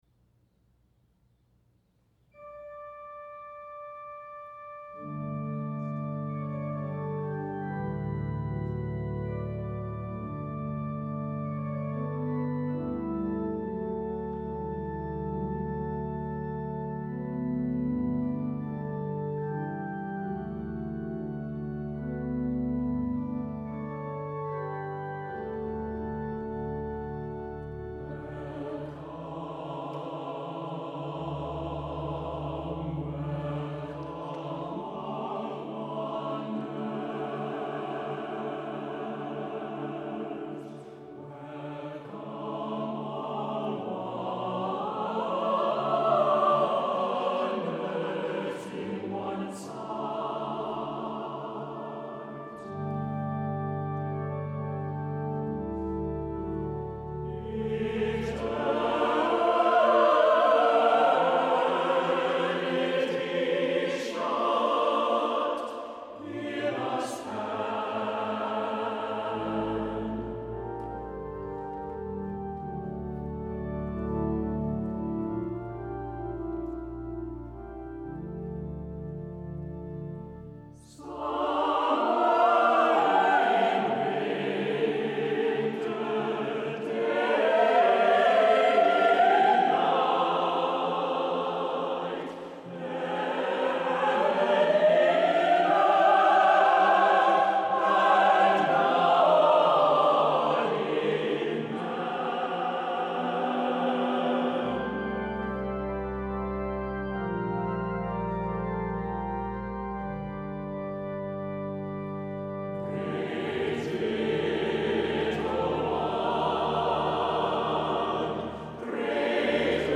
• Music Type: Choral
• Accompaniment: Brass Quintet, Organ
• Season: Christmas